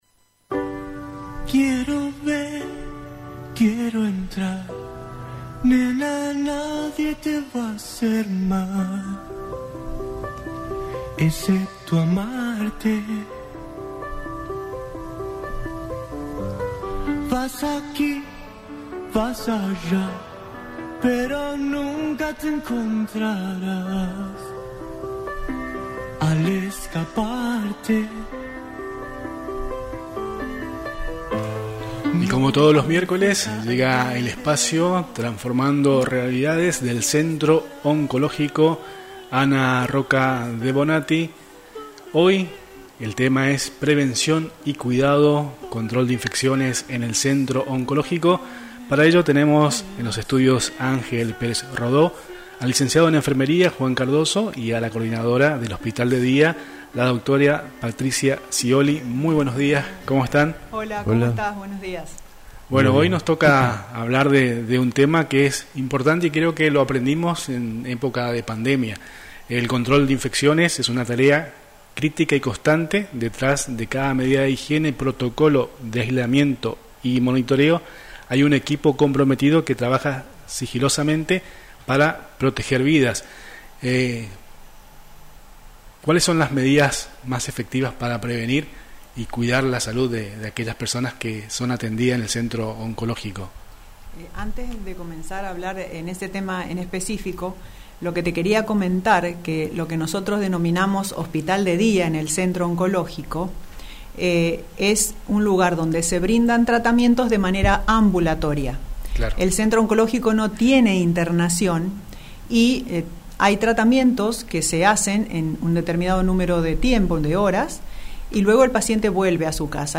En este espacio radial